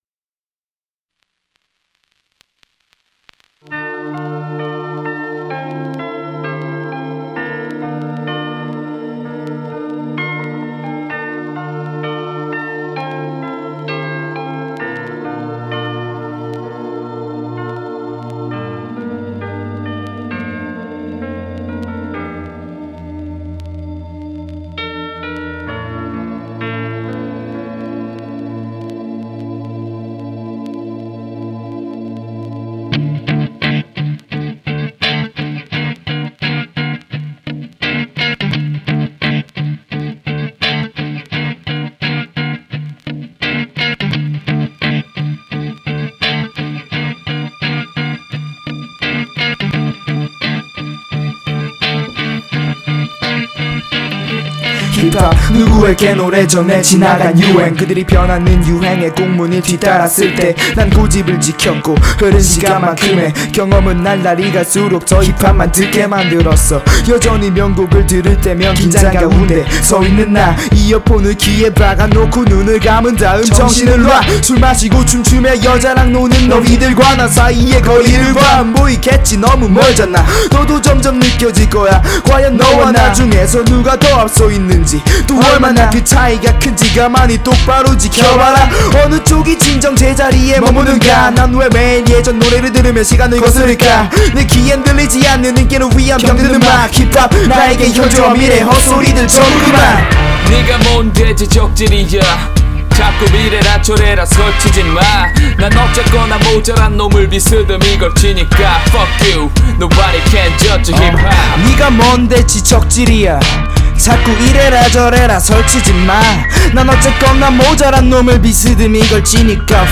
• [REMIX.]